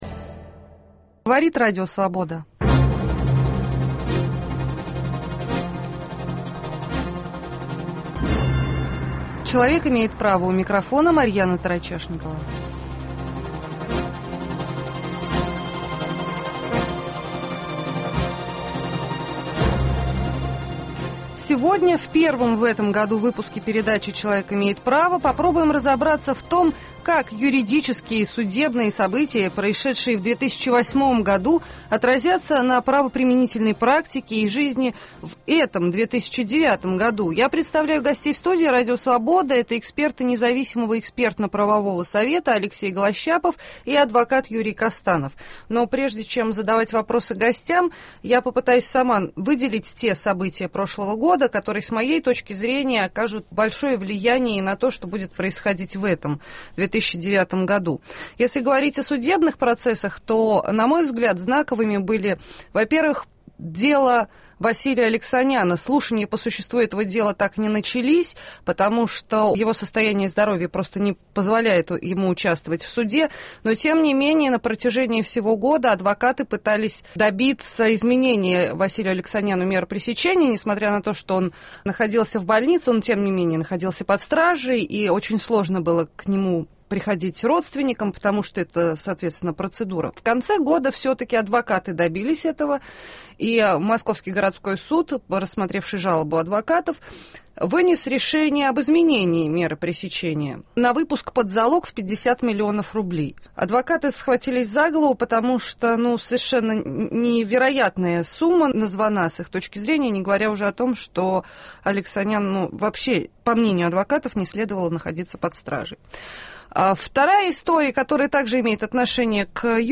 в студии РС